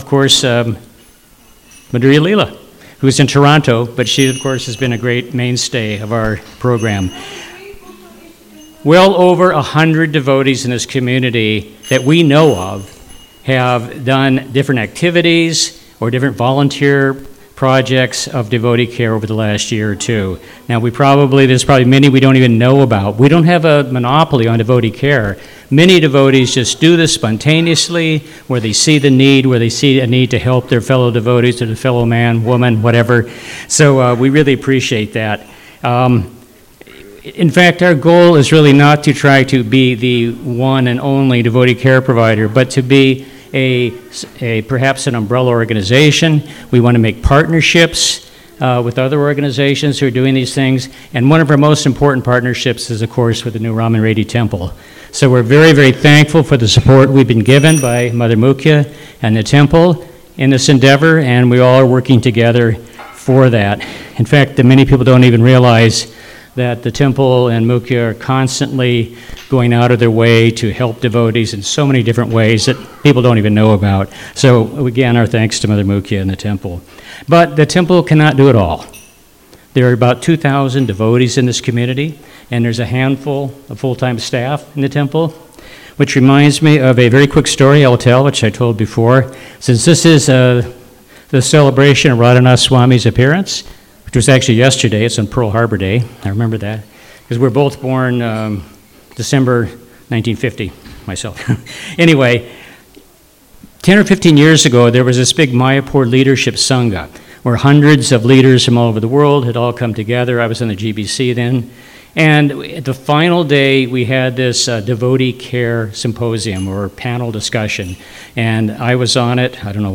Sunday Feast Lecture
2024. at the Hare Krishna Temple in Alachua, Florida,...